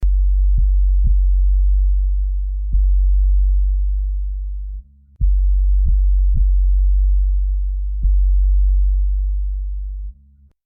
bass_3